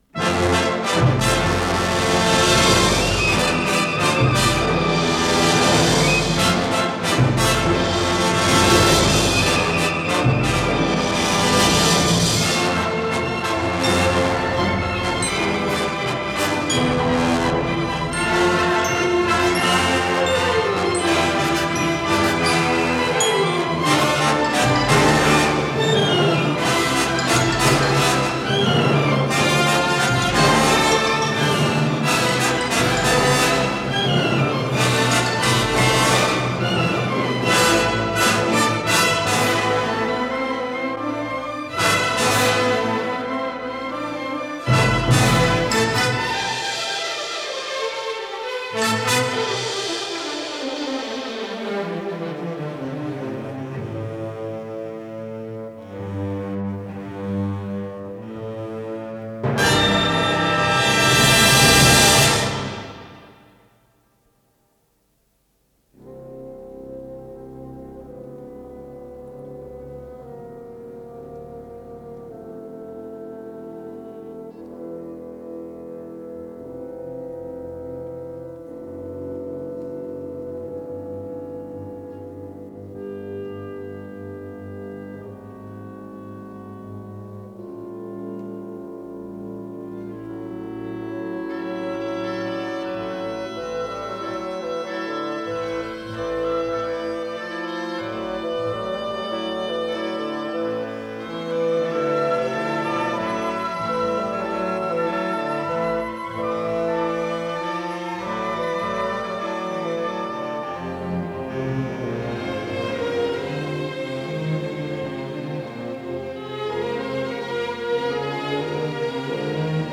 Симфоническая поэма, ля минор